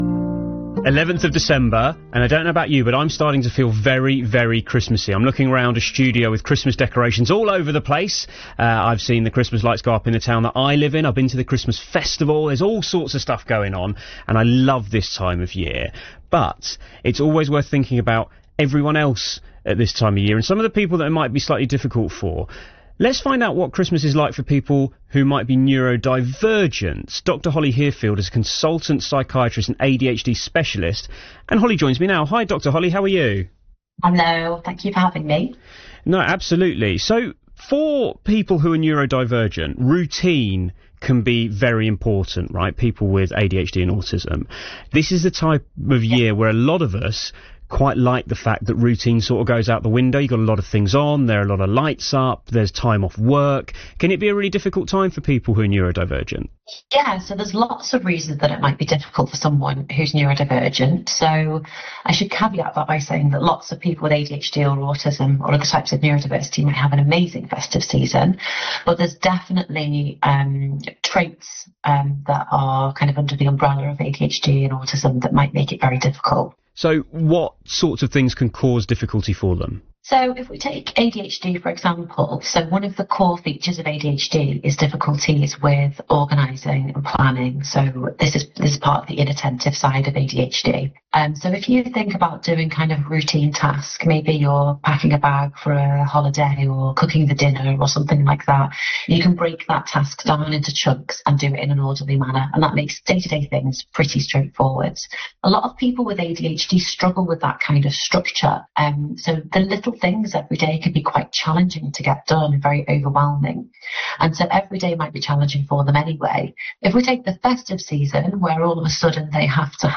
You can hear her radio interview by clicking here